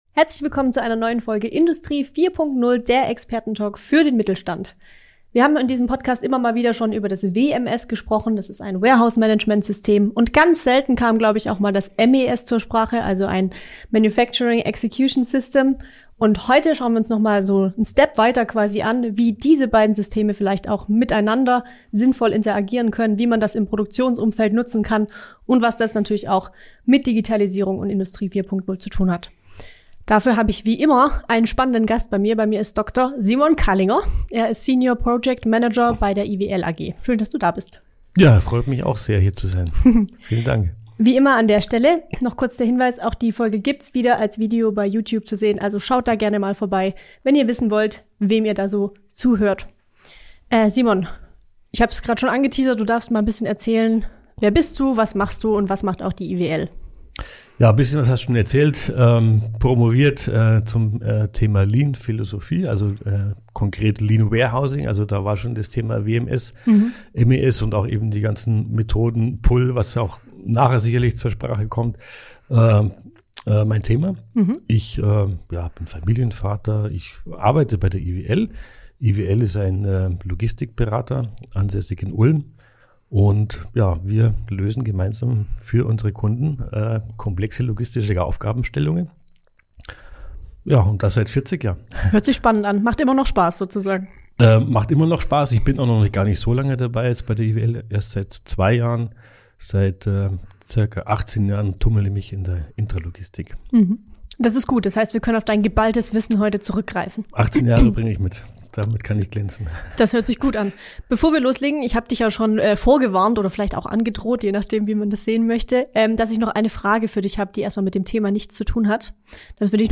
Industrie 4.0 – der Expertentalk für den Mittelstand